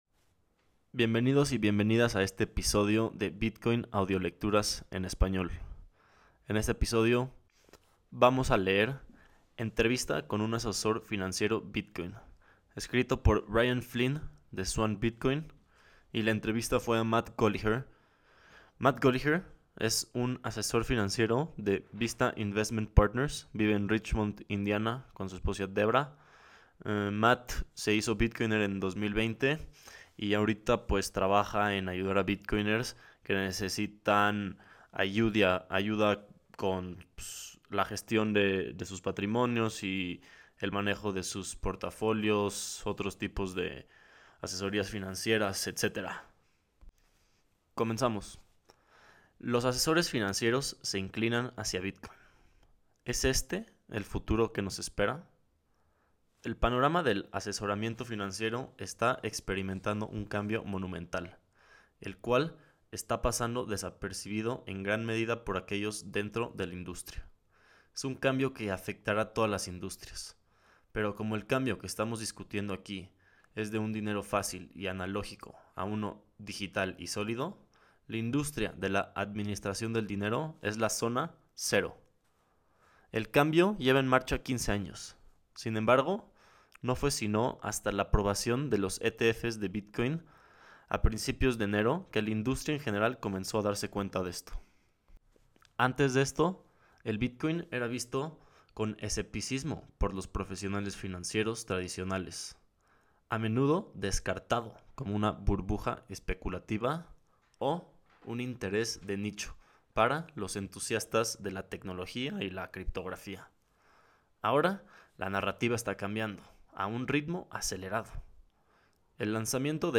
Entrevista a un asesor financiero bitcoiner